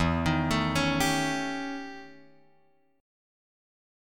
E Minor Major 13th